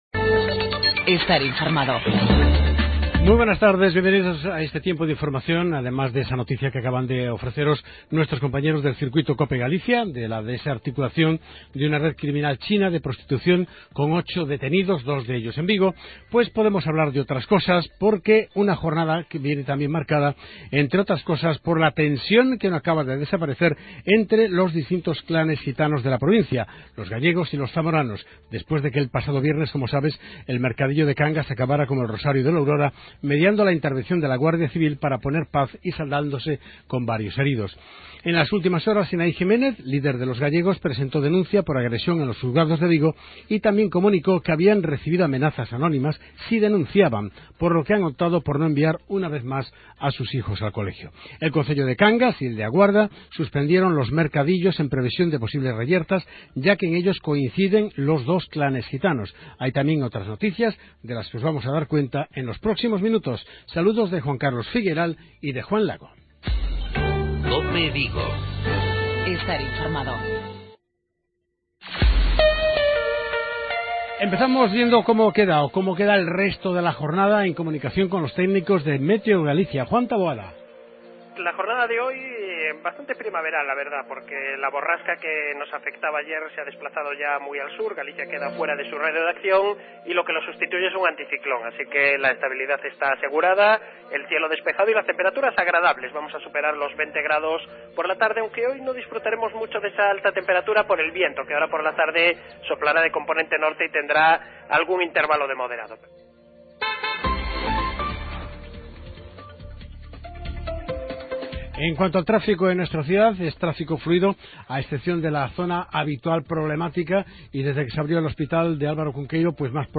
Informativos vigo